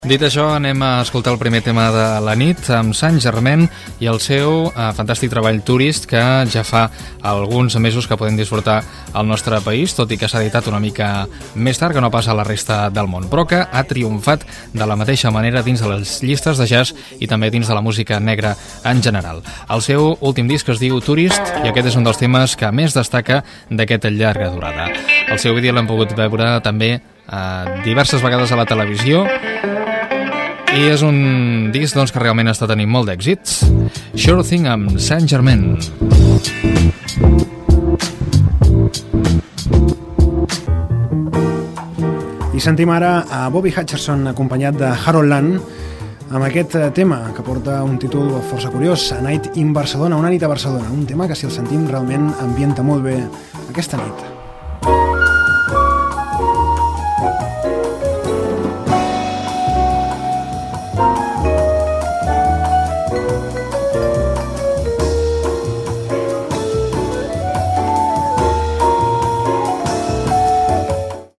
Presentació de temes musicals
Musical